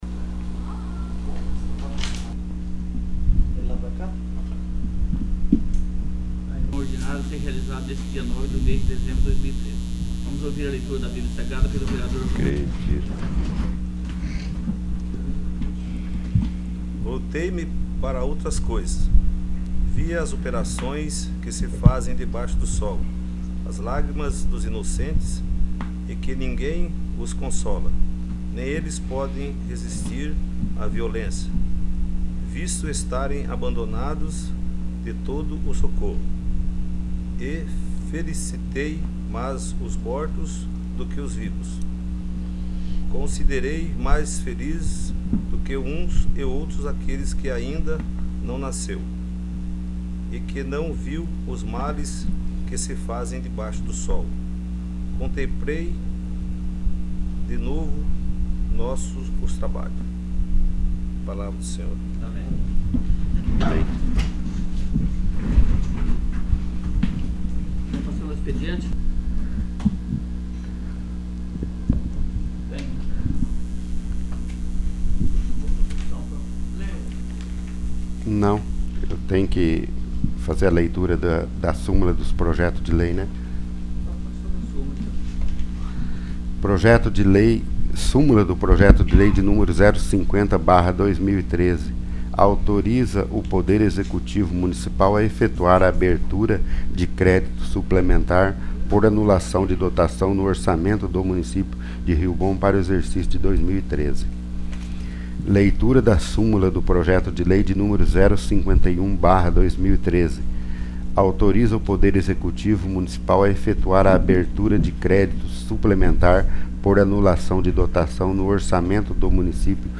40º. Sessão Ordinária